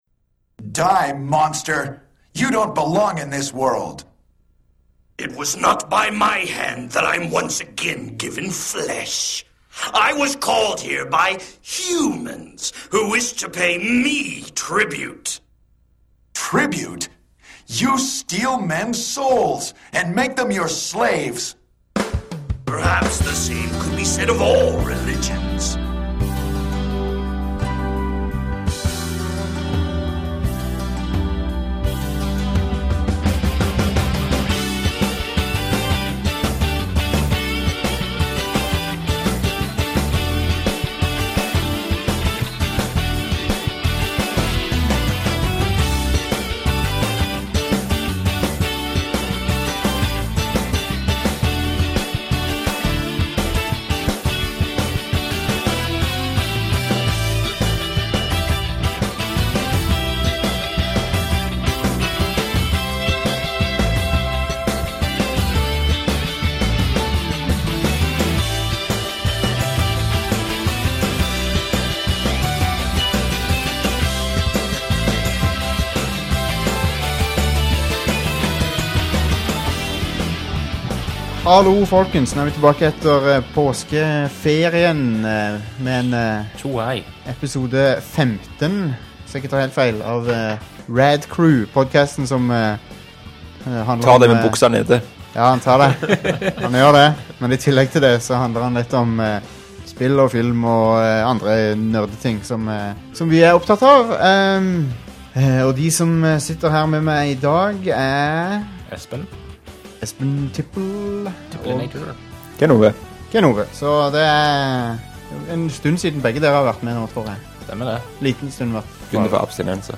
via Skype